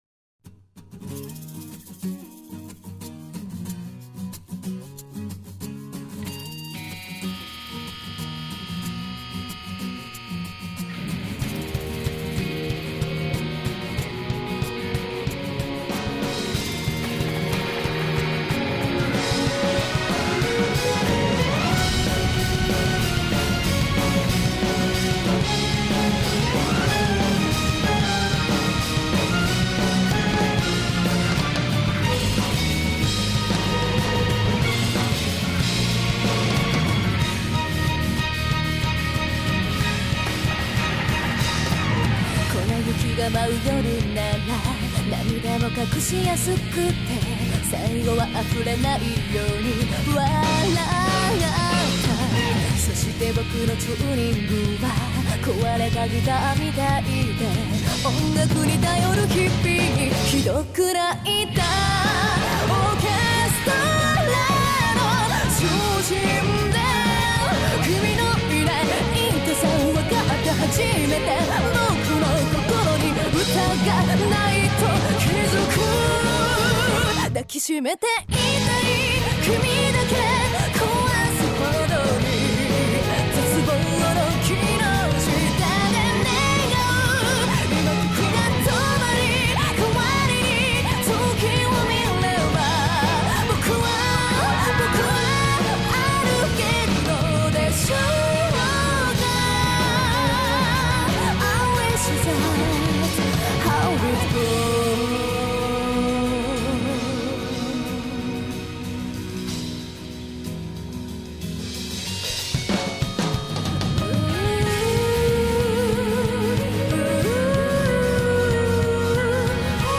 Orchestral_Fantasia.mp3